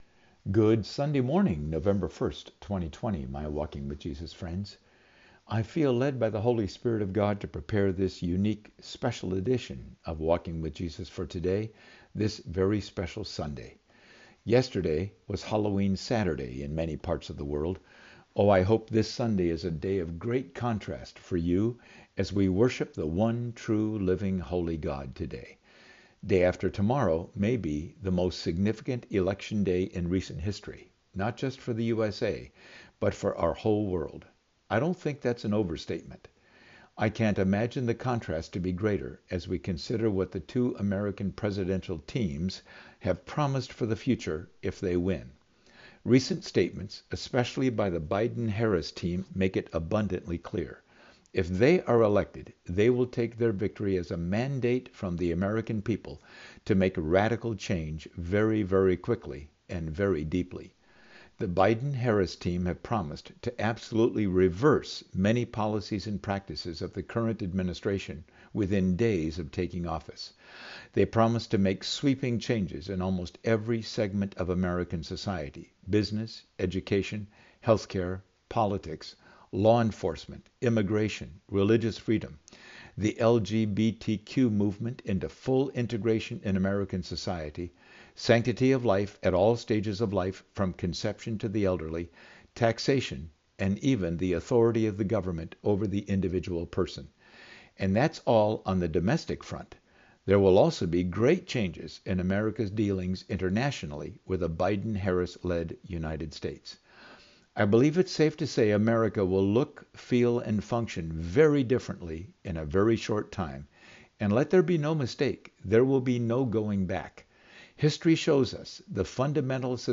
So I’ve attached a brief clip from that message, at the end of my special edition “Walking with Jesus” today.